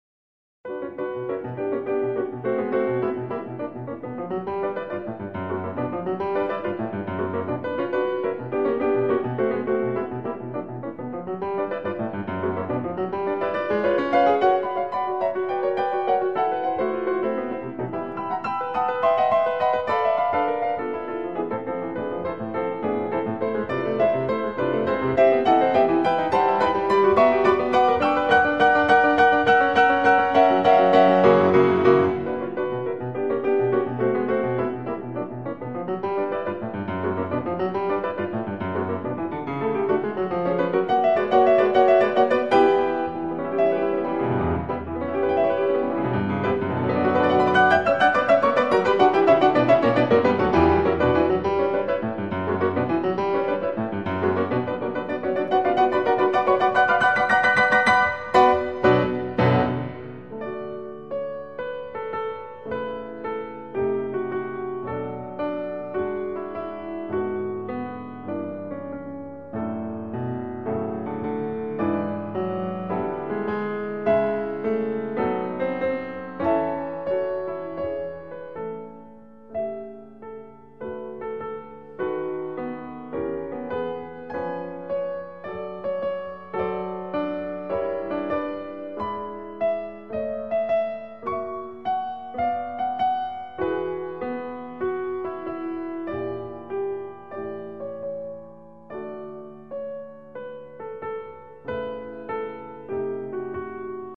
Композитор Петро Чайковський написав інструментальну п’єсу, також присвячену жнивам.
Прослухайте фортепіанну п’єсу Петра Чайковського і зверніть увагу на акцентований енергійний рух мелодії.